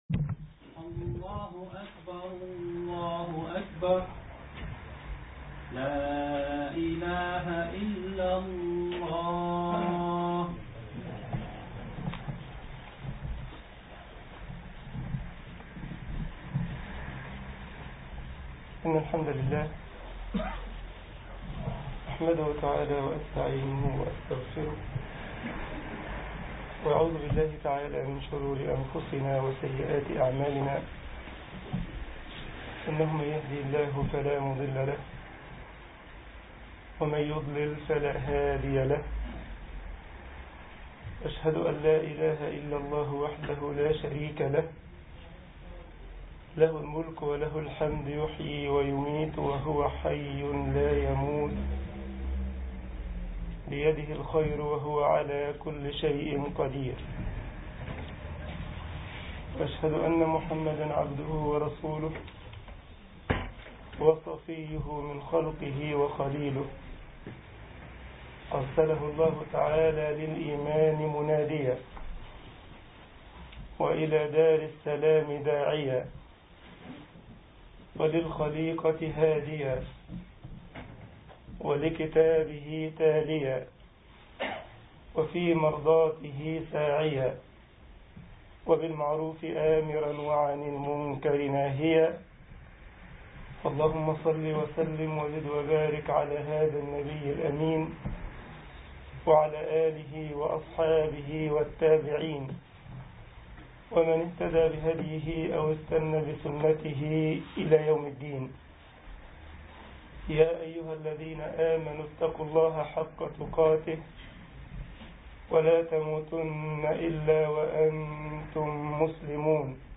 خطبة الجمعة
جمعية الشباب المسلمين بسلزبخ - ألمانيا